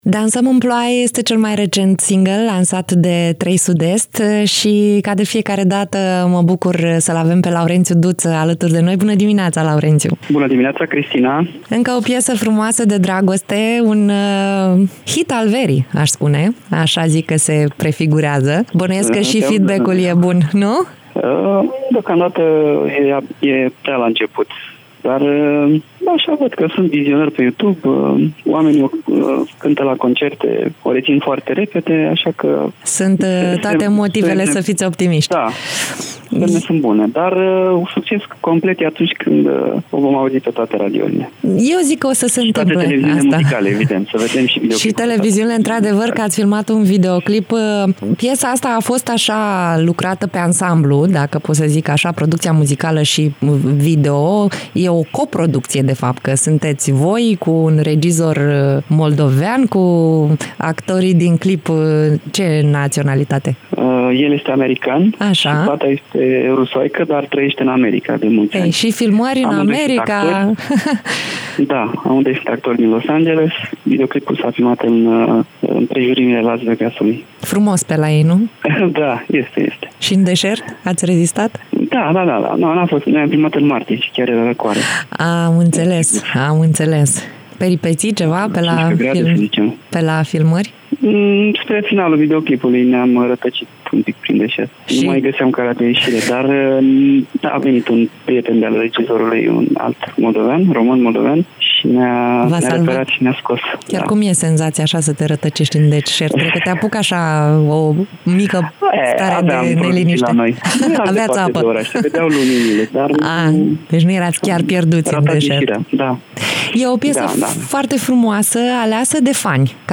03-iunie-interviu-laurentiu-duta.mp3